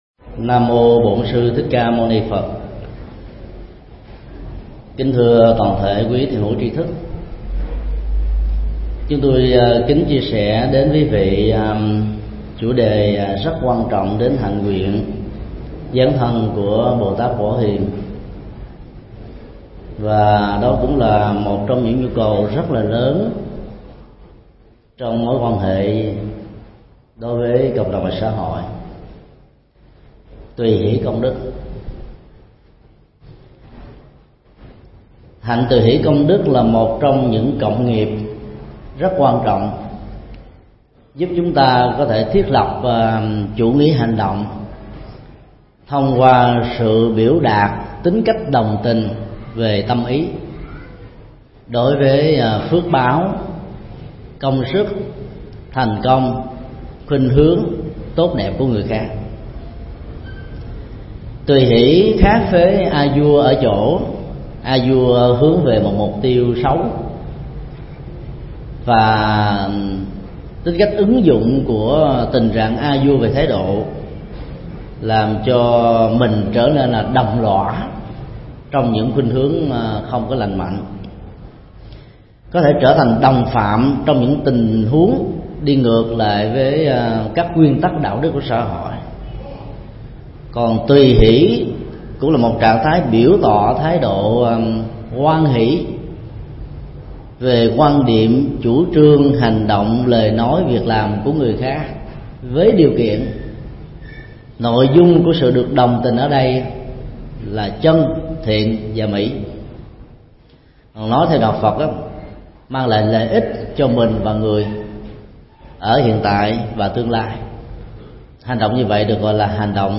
Tải mp3 Thuyết Giảng Tùy hỷ công đức 2
giảng tại Chùa Xá Lợi